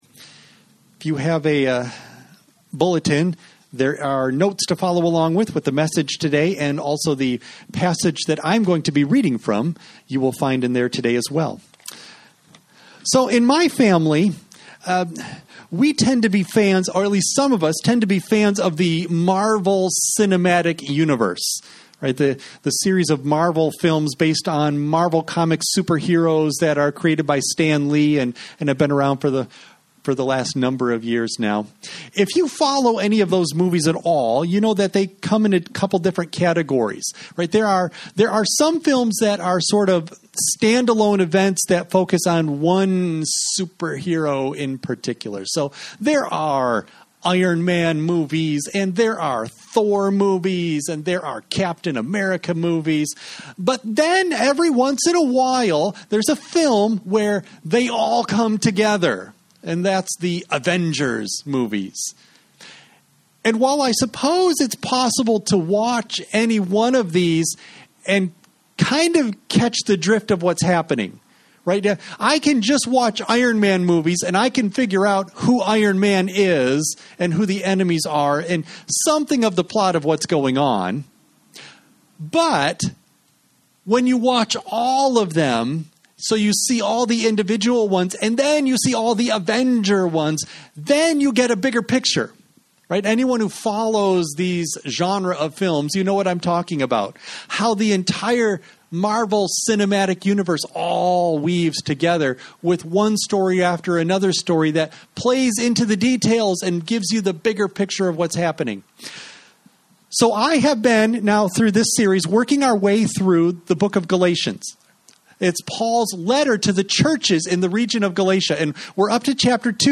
You may download and print the BULLETIN for this service as well as sermon NOTES for children from the Download Files section at the bottom of this page Worship Service September 20 Audio only of message